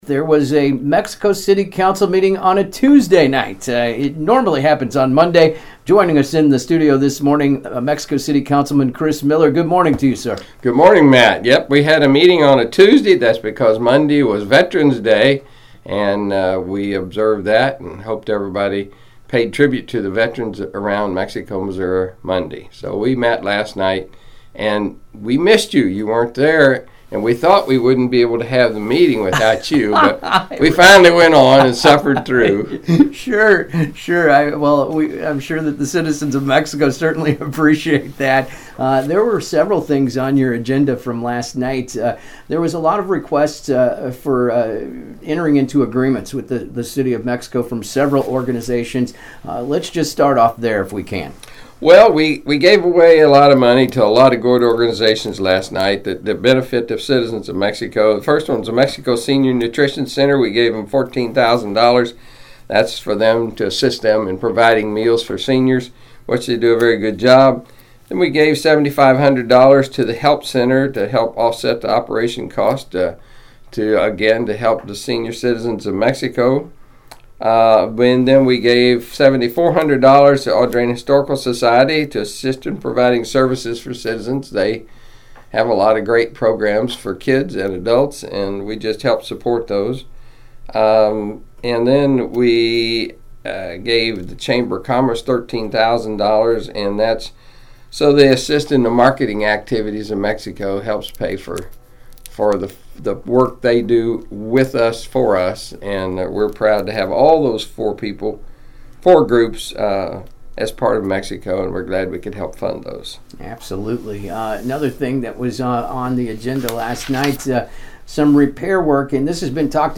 Mexico City Councilman Chris Miller Updates 11/12/2024 Mexico City Council Meeting On AM 1340 KXEO Am I Awake Morning Show